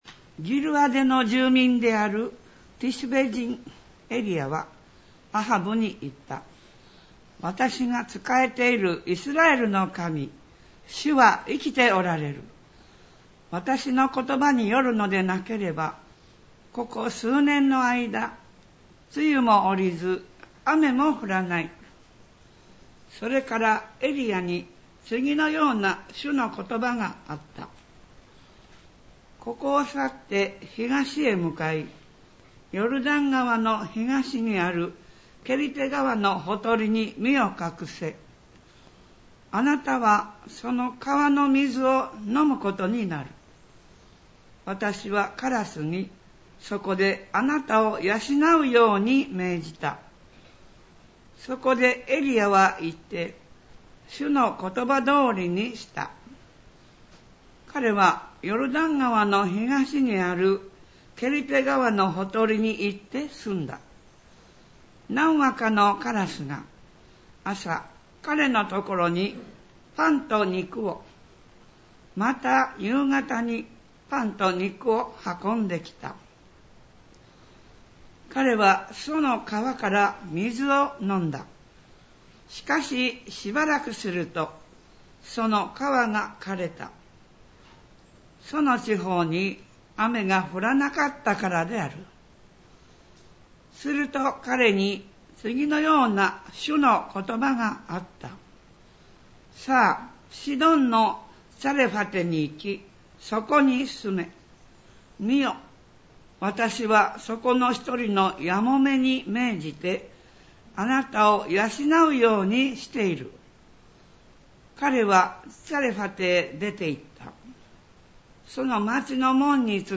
R4.8.14 礼拝式 | 舞鶴福音教会